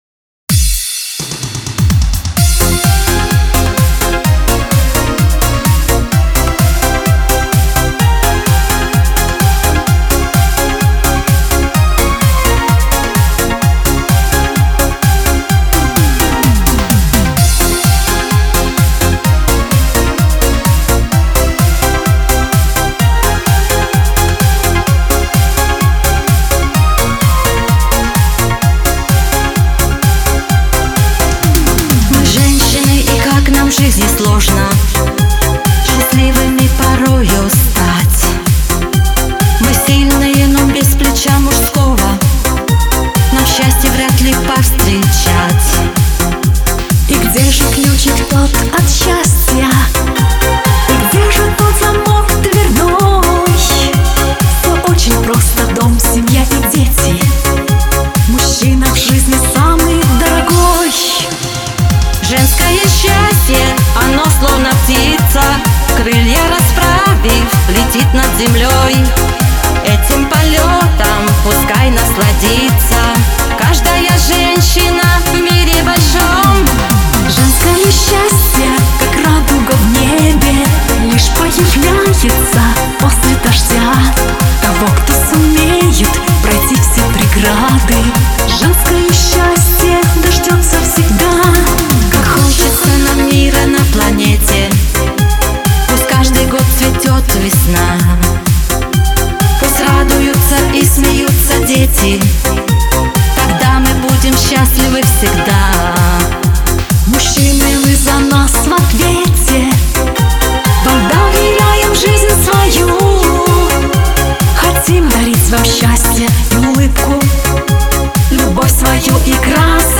эстрада , диско
pop
дуэт